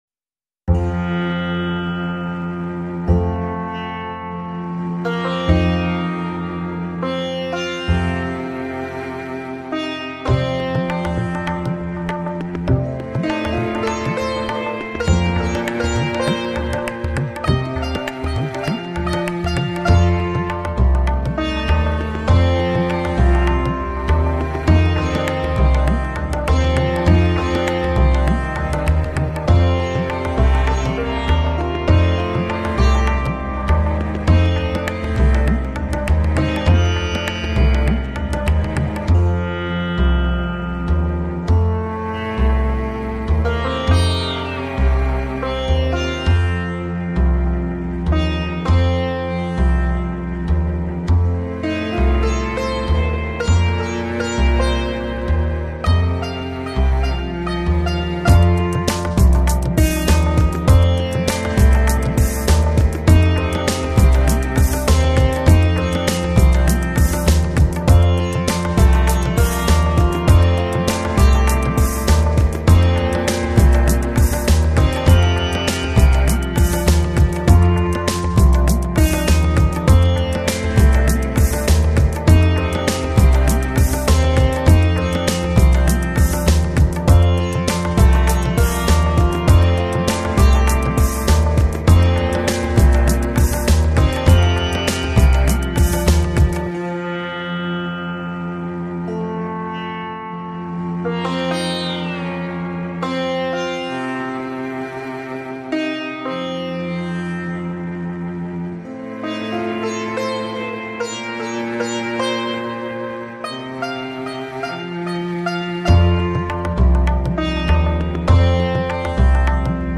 это завораживающий трек в жанре инди-поп